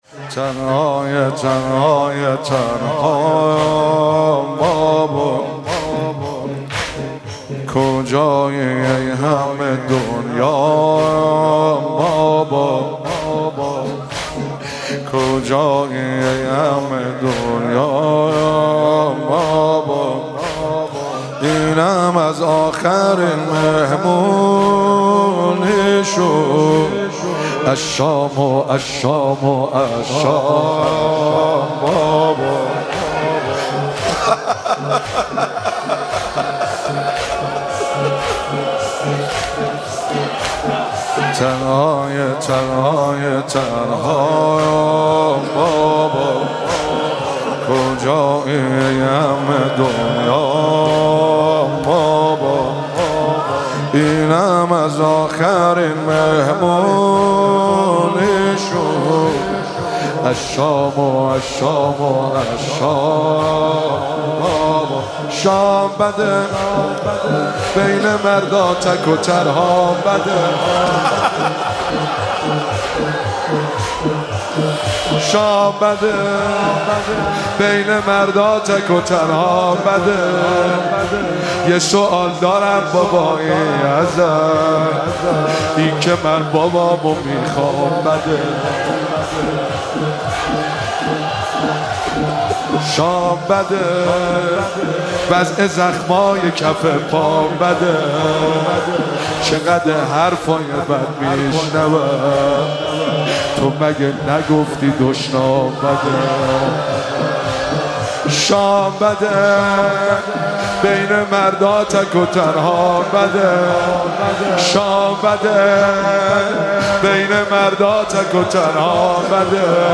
مراسم شهادت حضرت رقیه (س)- شهریور 1401